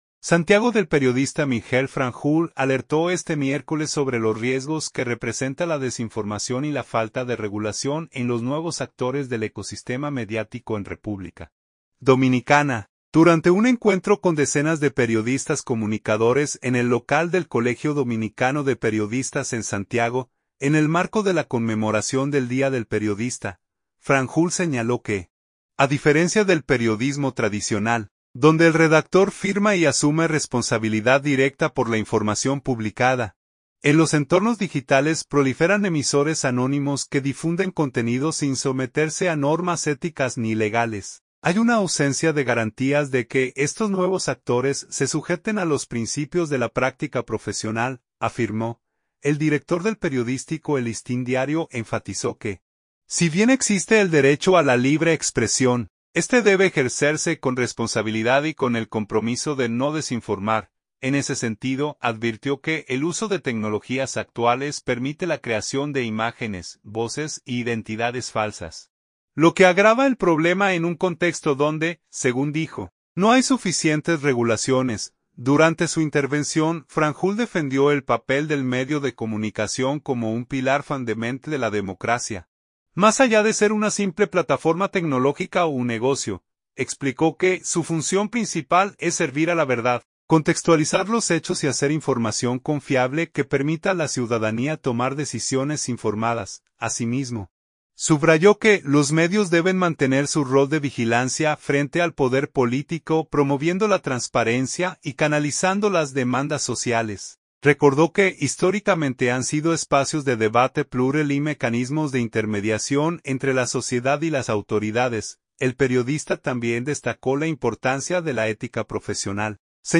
El encuentro formó parte de las actividades conmemorativas del Día del Periodista, donde,se conversatorio "Responsabilidad de los Medios de Comunicación en la Era Digital", impartida el profesional de la comunicación reflexionó sobre los desafíos actuales de la profesión en el país